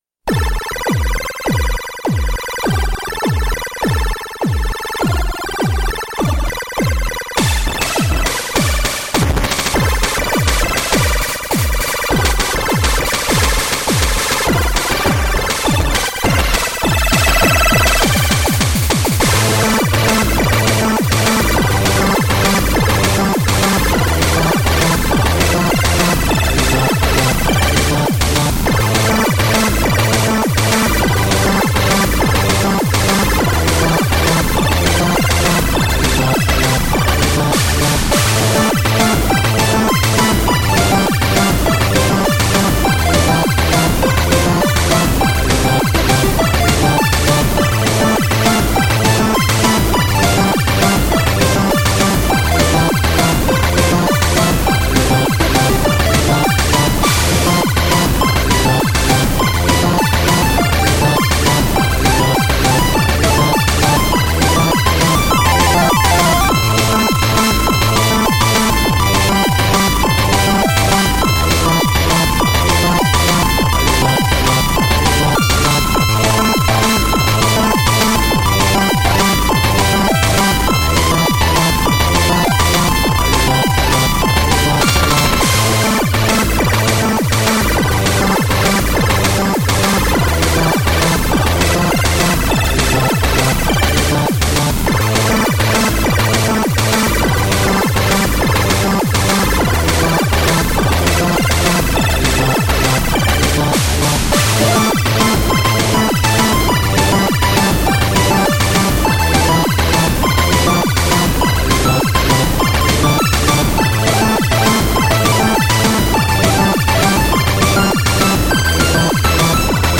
A-minor 203 bpm.